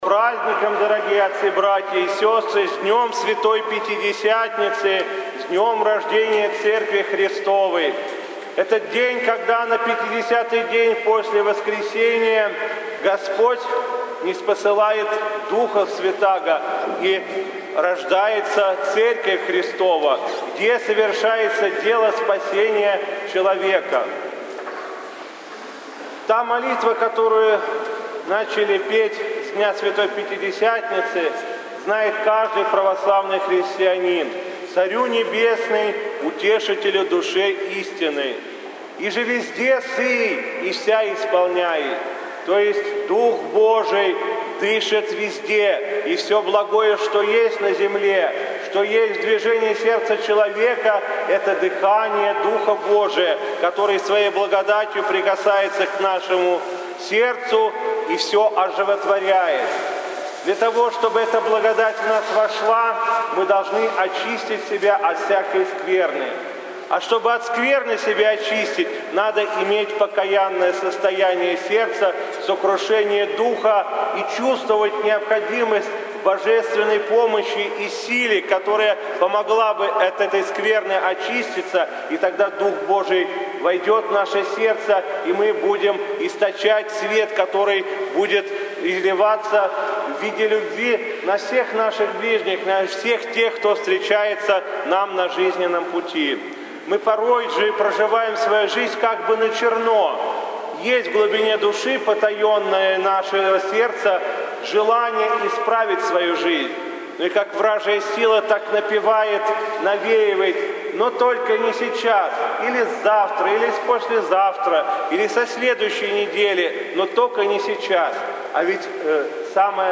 В праздник Святой Троицы владыка Игнатий совершил Литургию в Софийском кафедральном соборе Вологды